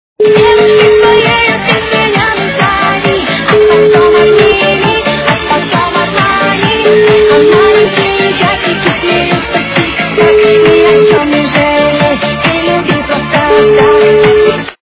русская эстрада
качество понижено и присутствуют гудки